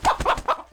combat / creatures / chicken / he / hurt1.wav
hurt1.wav